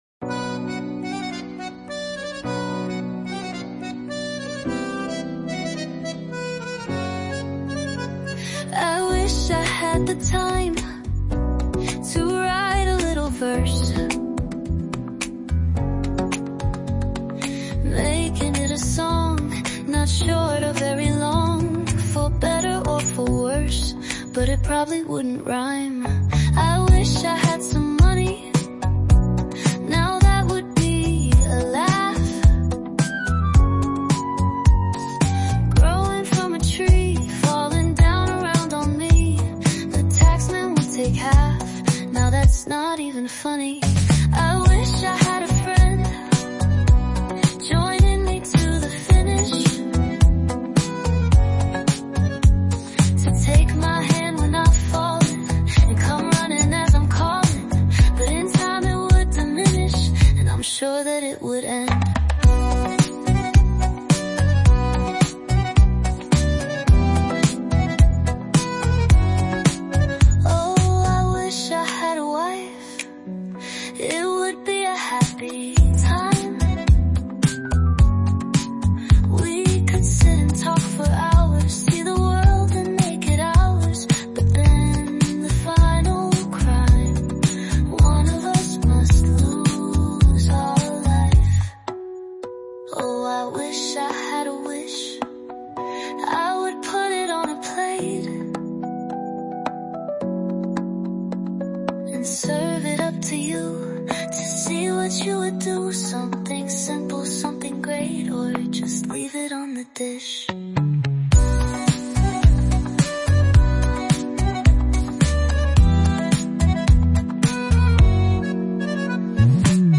I'm glad you found the time to write these lyrics and put them to such jaunty music.